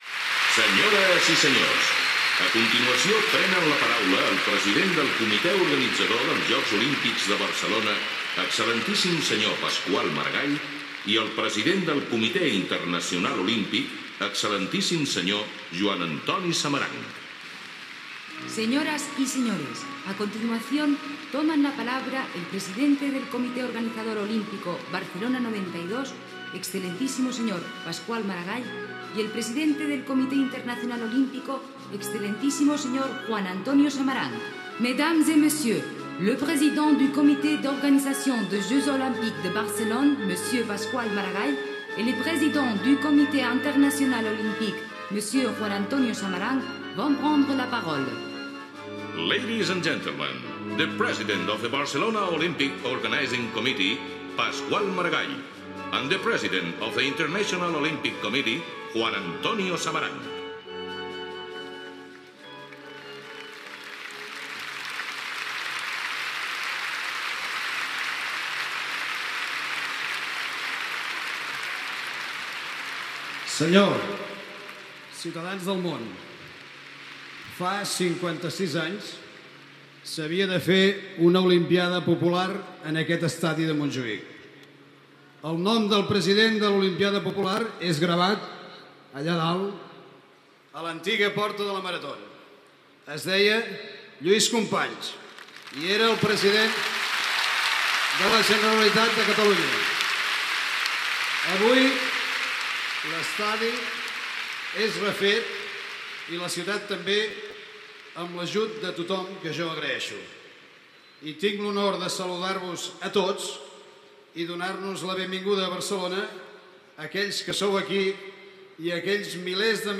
Presentació i discurs de l'alcalde de Barcelona i president del Comitè Olímpic Organitzador Barcelona 1992 Pasqual Maragall a la cerimònia d'inauguració dels Jocs Olímpics de Barcelona.
Informatiu